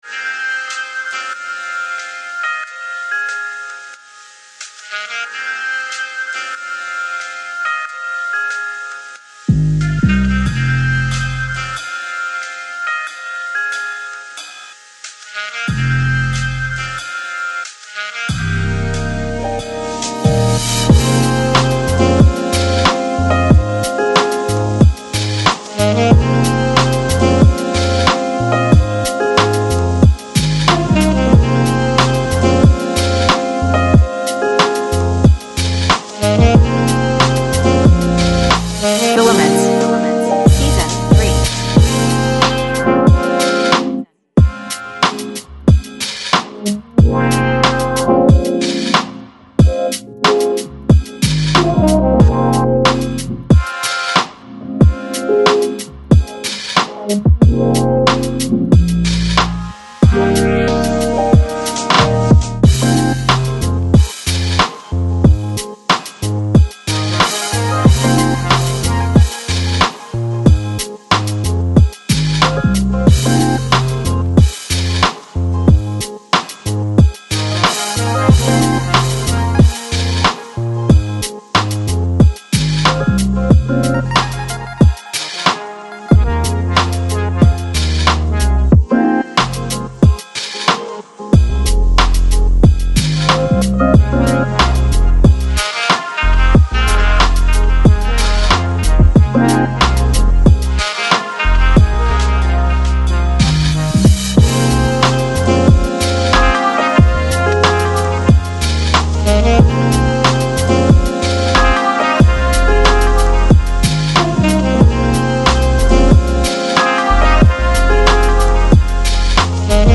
Жанр: Lo-Fi, Lounge, Chillout